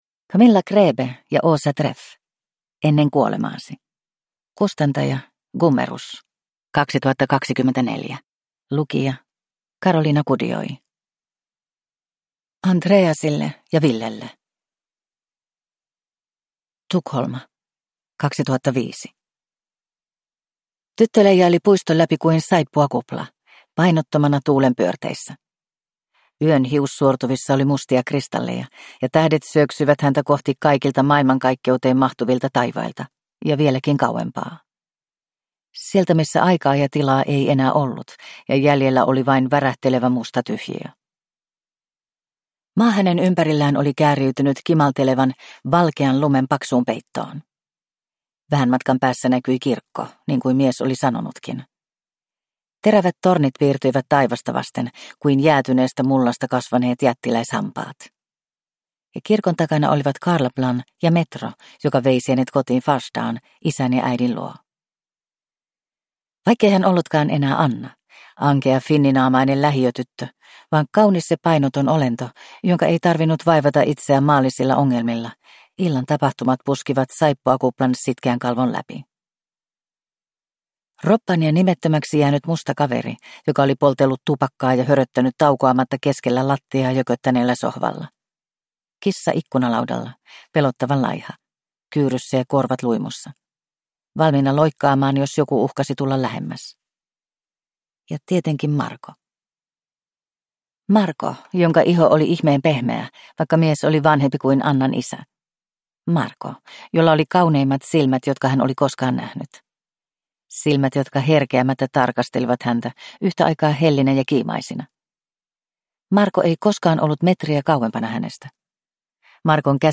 Ennen kuolemaasi – Ljudbok